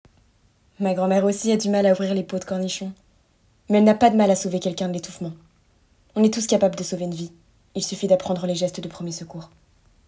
Bandes-son
- Mezzo-soprano